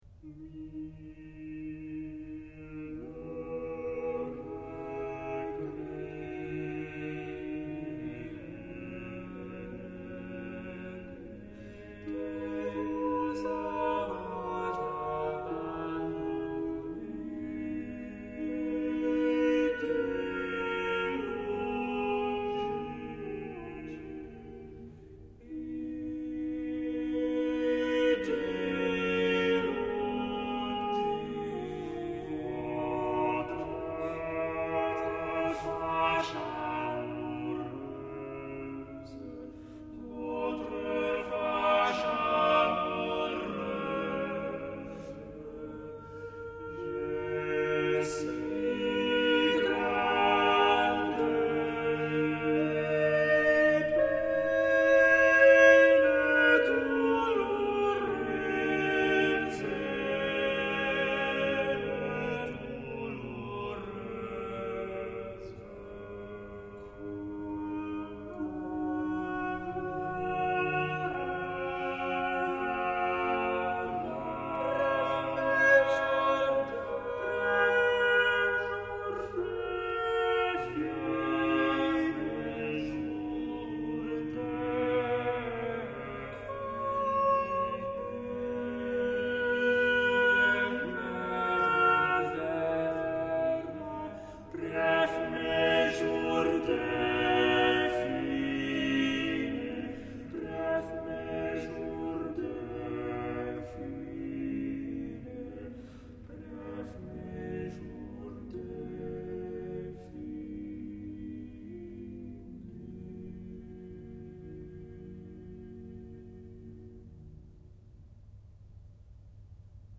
To Mille Regretz είναι ένα αριστουργηματικό chanson (ήτοι πολυφωνικό τραγούδι με γαλλικό κείμενο, συνήθως με ερωτικό ή περιγραφικό περιεχόμενο) του Josquin.
Είναι γραμμένο στον πλάγιο του Φρύγιου, που από το μεσαίωνα θεωρείται ο πιο λυπητερός τρόπος. Λόγω του περιεχομένου φτάνει μέχρι στο να παραβεί τη σύμβαση της 3ης Πικαρντί: το μοναδικό απ’ όσο γνωρίζω αναγεννησιακό κομμάτι που τελειώνει με ελάσσονα συγχορδία (και μάλιστα με τον εμφανέστερο τρόπο: με τη σοπράνο να τελειώνει ανεβαίνοντας προς την 3η της συγχορδίας).
Τραγουδά το Hilliard Ensemble